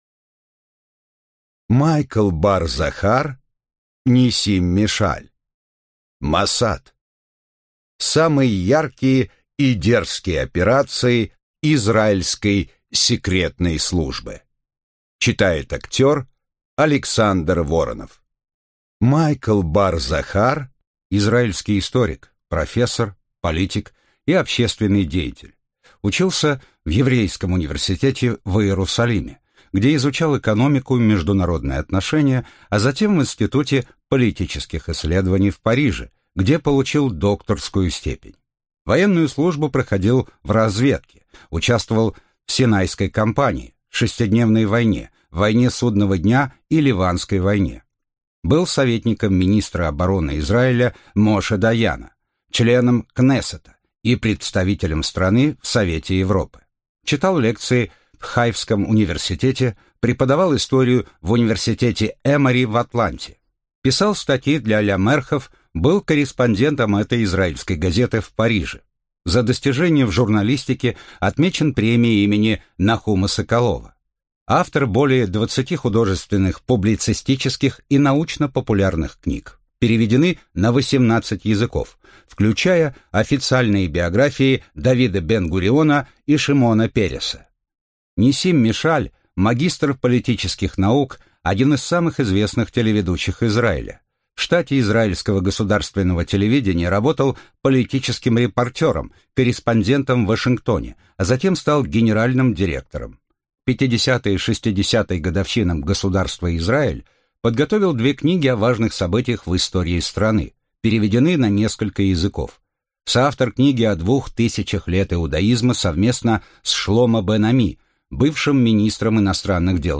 Аудиокнига Моссад. Самые яркие и дерзкие операции израильской секретной службы | Библиотека аудиокниг